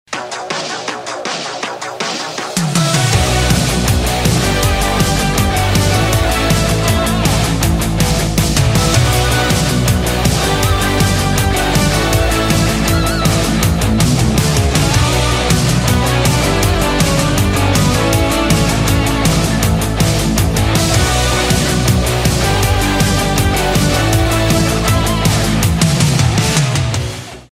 • Качество: 320, Stereo
гитара
Synth Pop
Electronic
без слов